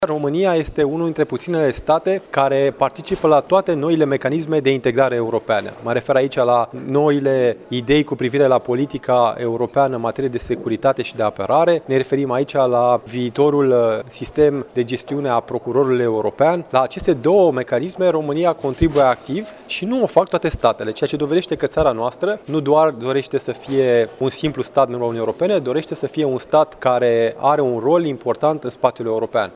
Ministrul delegat pentru Afaceri Europene, Victor Negrescu, a mai declarat, astăzi, la Iaşi, că România nu doreşte o Europă a regiunilor separate, ci una a cetăţenilor, care să garanteze drepturile acestora.